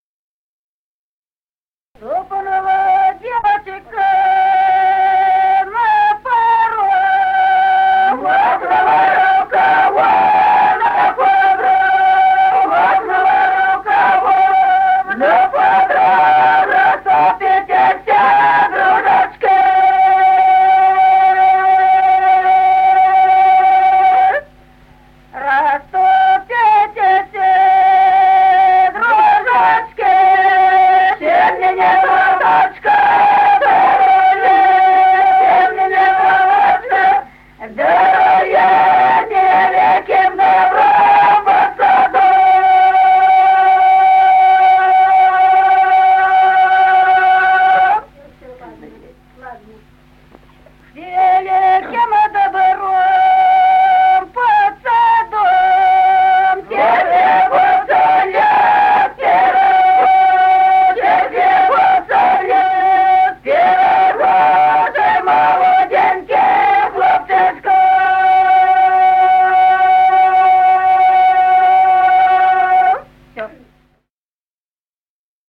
Народные песни Стародубского района «Ступнула девочка», свадебная.
с. Остроглядово.